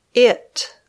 Click on a pronoun to hear the pronunciation.